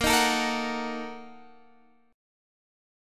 BbM7sus4#5 chord